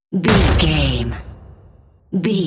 Cinematic drum hit trailer
Sound Effects
Epic / Action
Atonal
heavy
intense
dark
aggressive